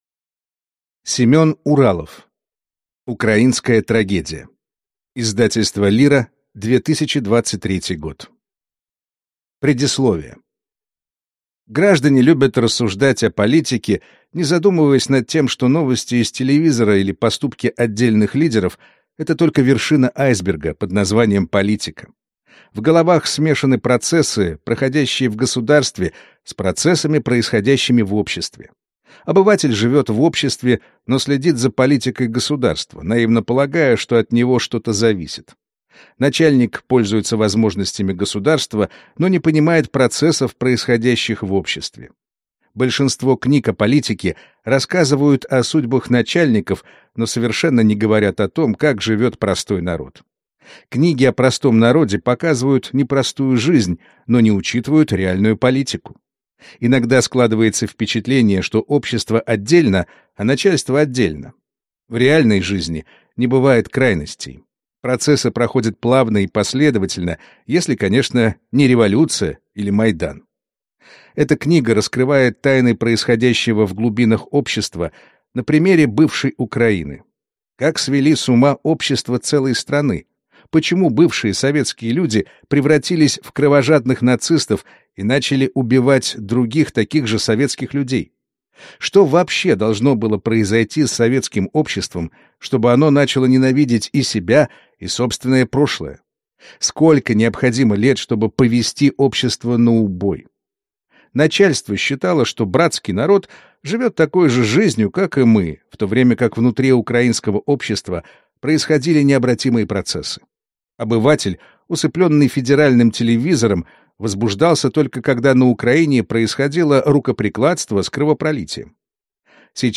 Аудиокнига Украинская трагедия. Технологии сведения с ума | Библиотека аудиокниг